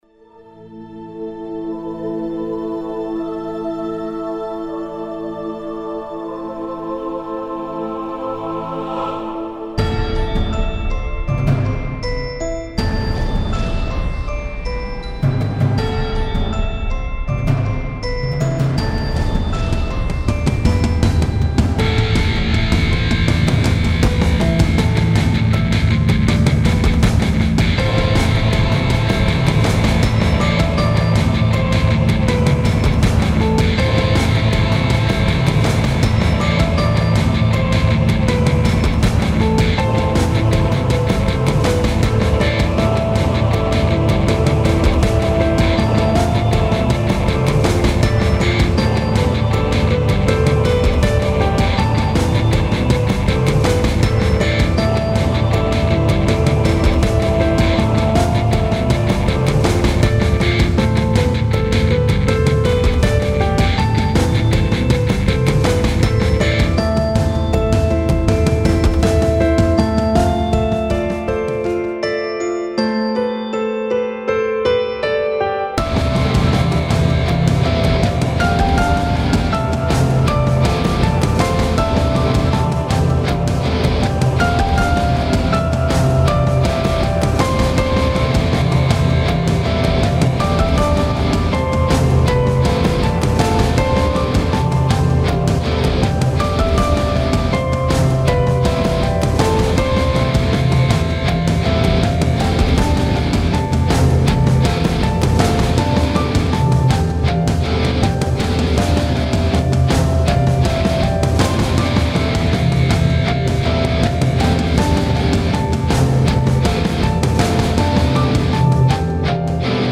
Like back then, these tracks were created with Magix Music Maker, which offers a very unique sound in addition to its simplicity.